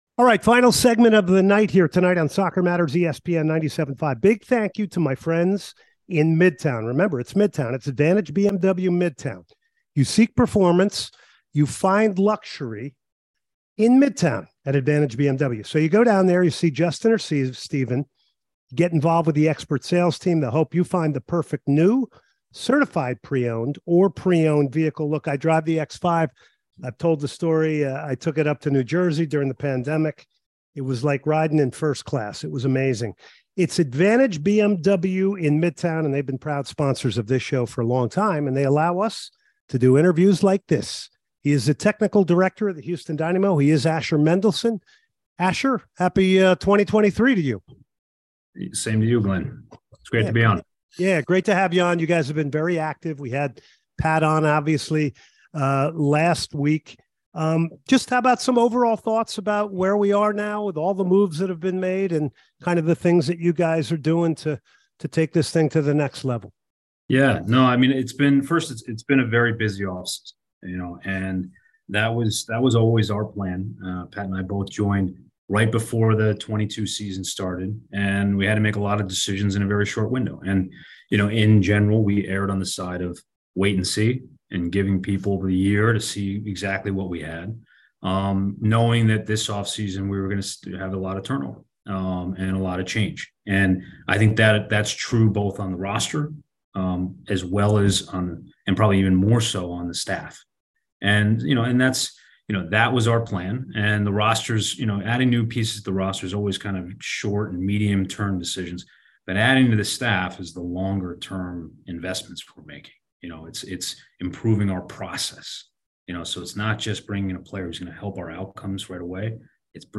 A two part interview to end off this hour